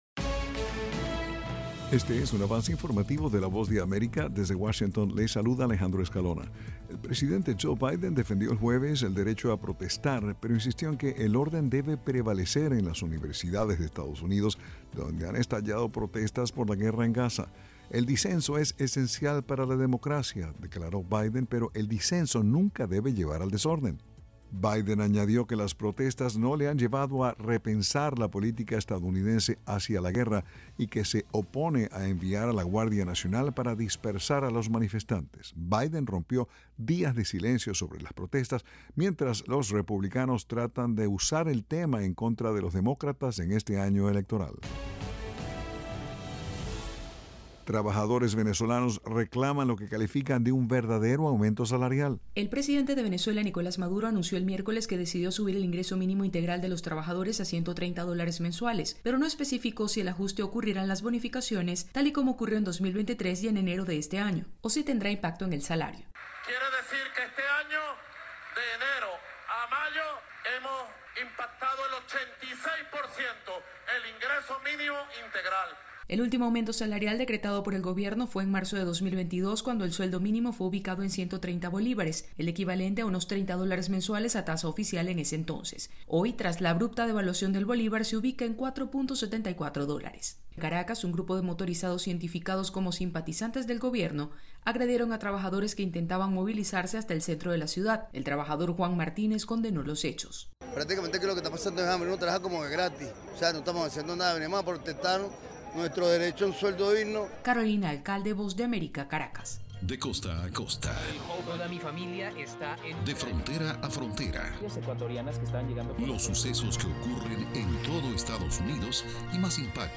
Este es un. avance informativo presentado por la Voz de América en Washington.